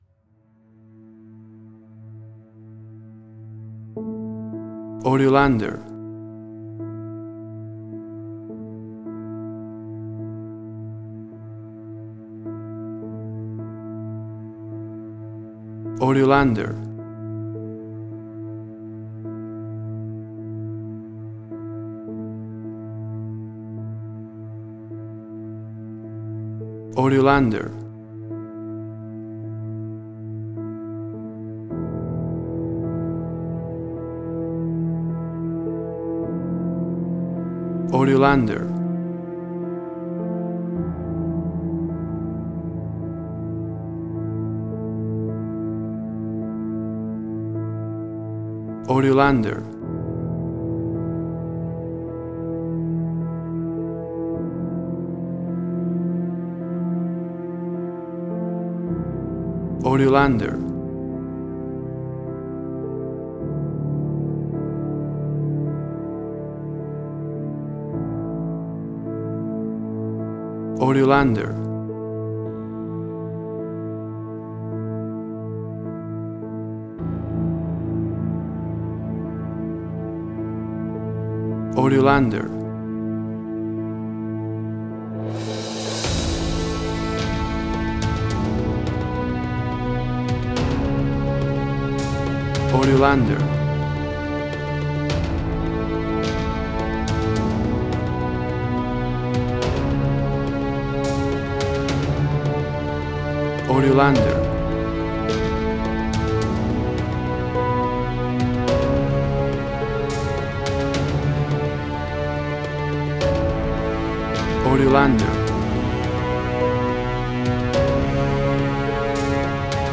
Suspense, Drama, Quirky, Emotional.
WAV Sample Rate: 16-Bit stereo, 44.1 kHz
Tempo (BPM): 106